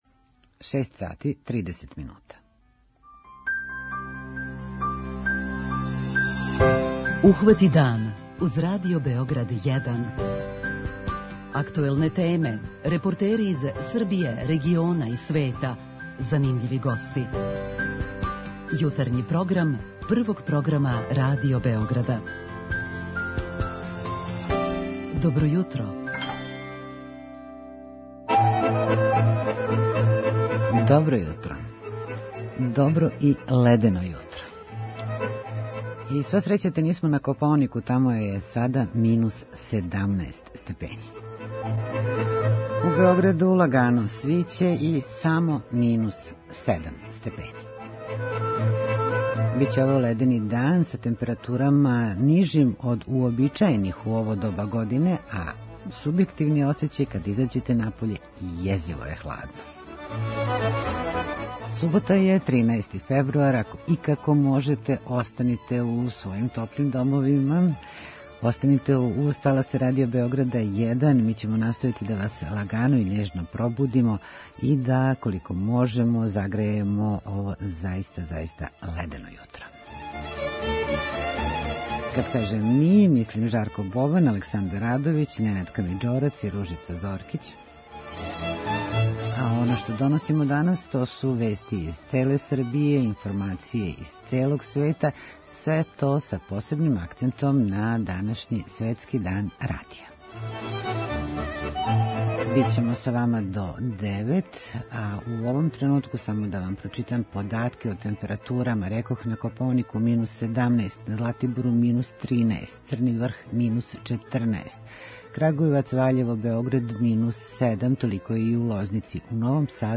Издвојили смо људе који годинама доприносе очувању културне баштине и њеном представљању на таласима нашег Јавног сервиса и неке од многобројних звучних записа које чувамо у архиву нашег радија.